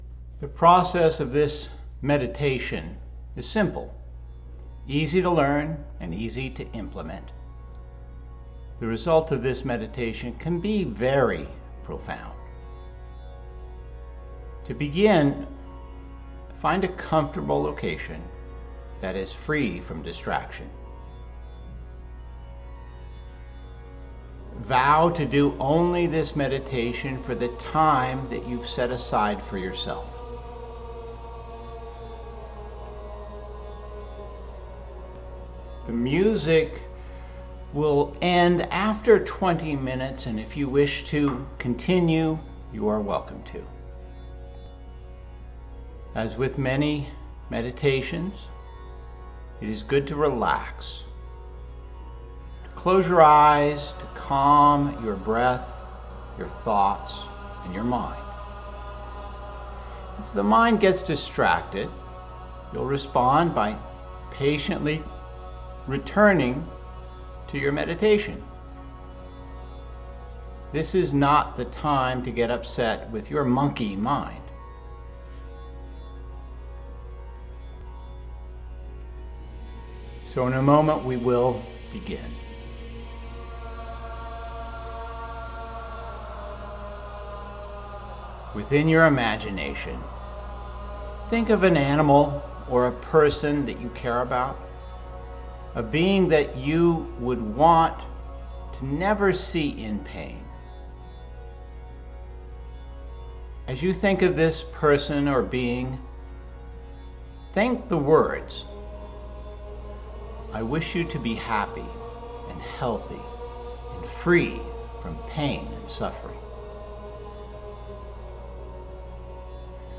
CompassionMeditationwithmusic20minutes.mp3